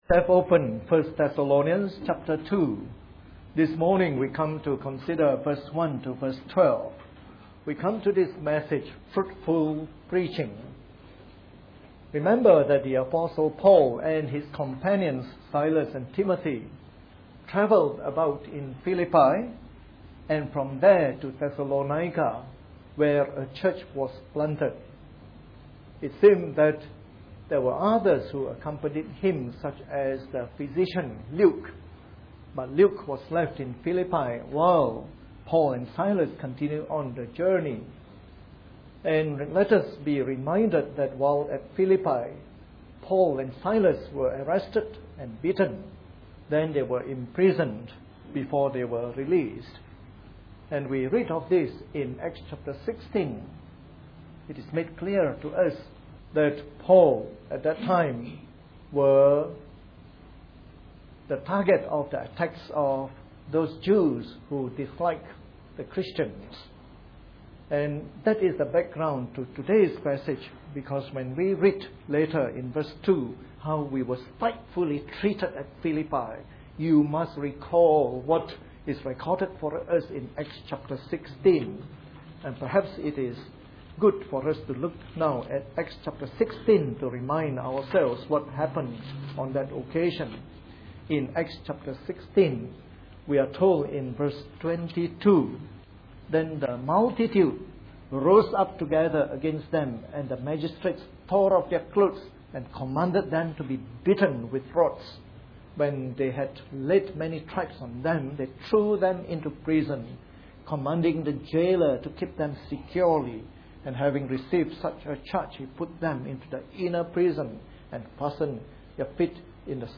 A sermon in the morning service from our series on 1 Thessalonians.